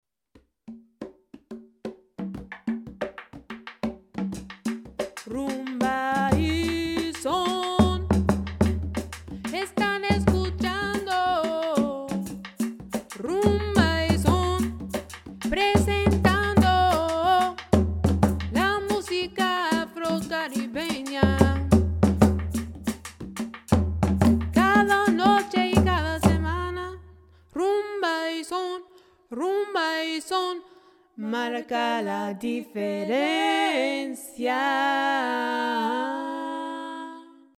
a salsa program
Percussion
Vocals